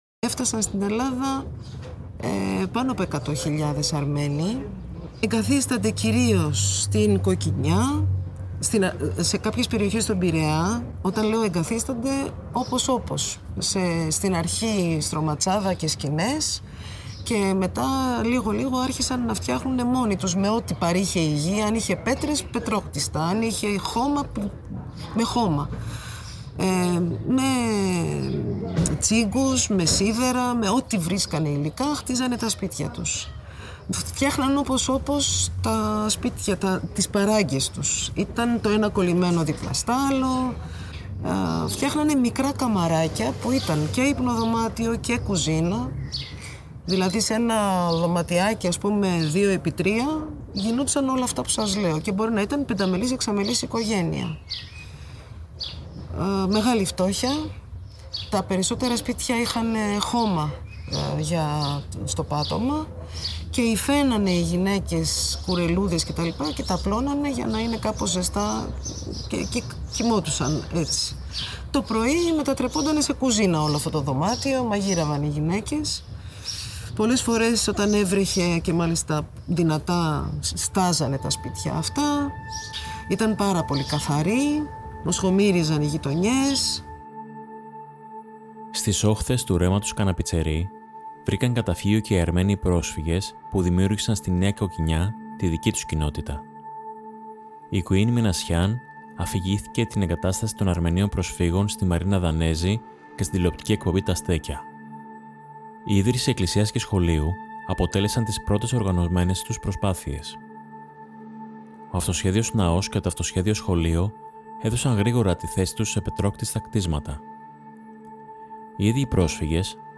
(Αποσπάσματα συνέντευξης